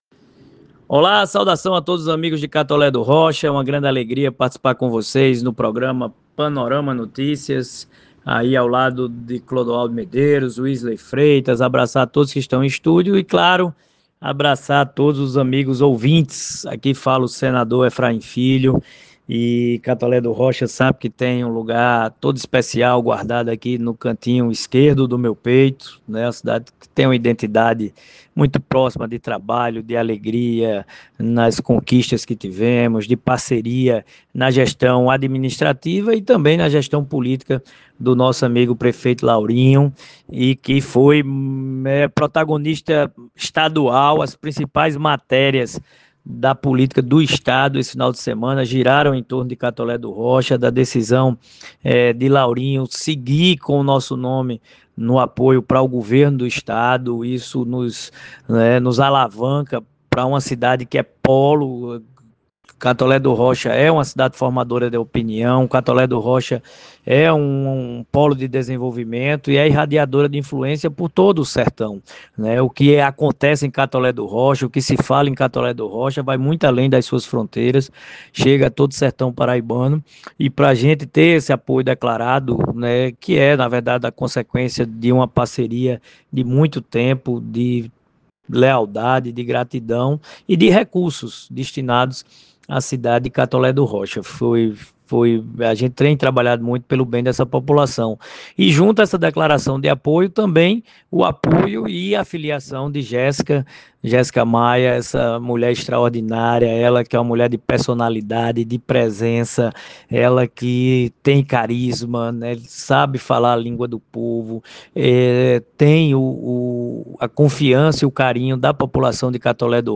O senador Efraim Filho, pré-candidato ao Governo do Estado, participou do programa Panorama Notícias na segunda-feira (30) e reforçou o fortalecimento de sua base política no Sertão, com destaque para o apoio do grupo liderado pelo prefeito Laurinho Maia.